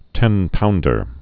(tĕnpoundər)